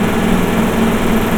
KART_Engine_loop_0.ogg